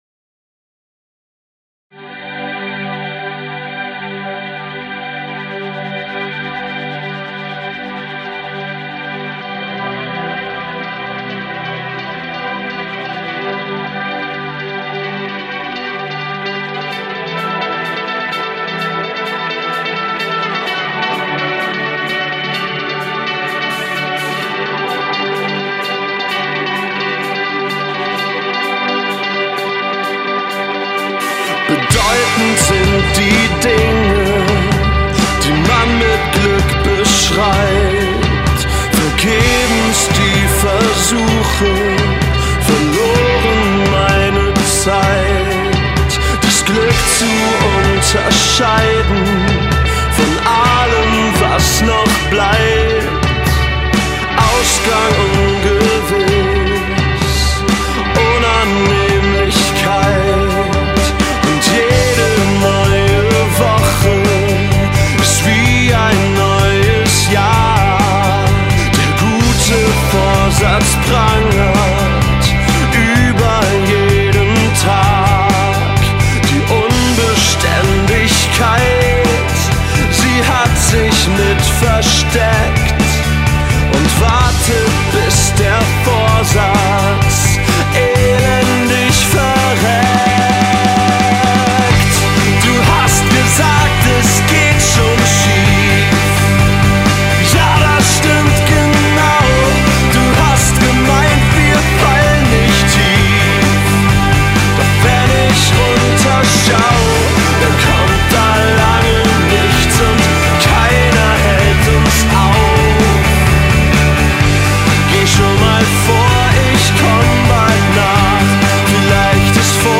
Die Musik ist laut, schnörkellos und ein bisschen Punk.